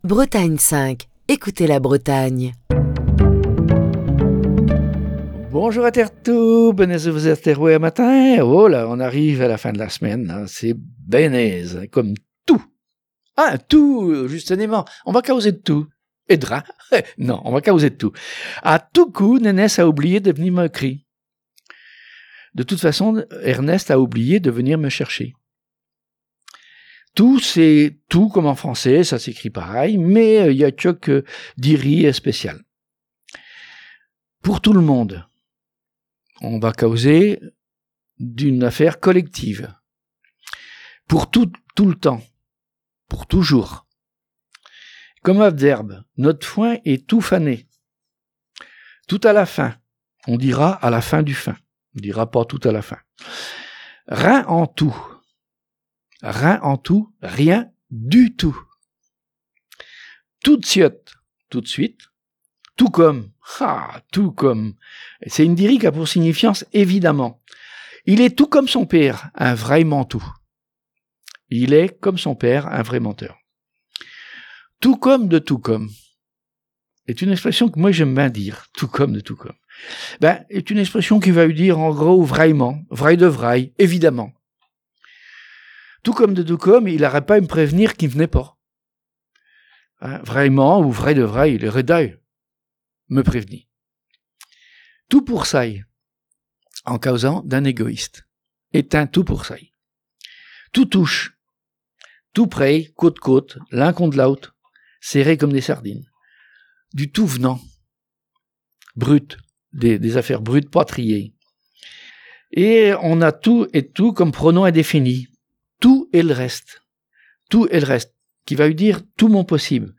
Chronique du 8 octobre 2021.